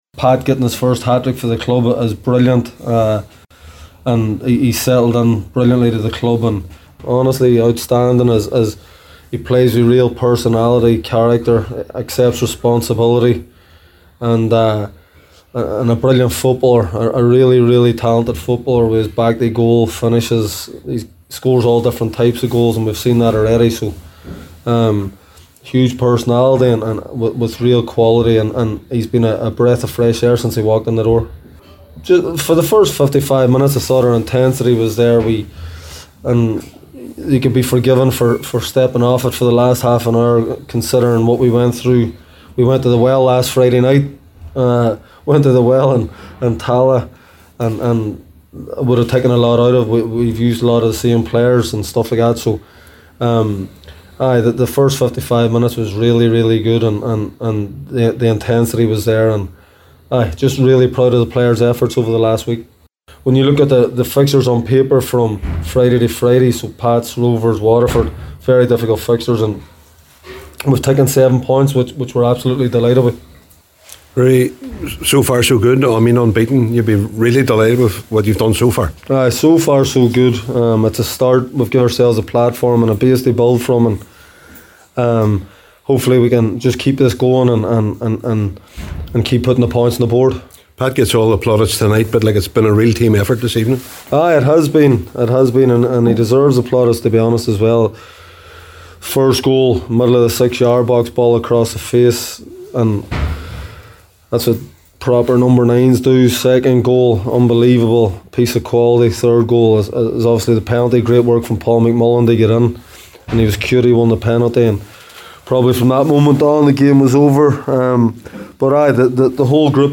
Reaction
after the game…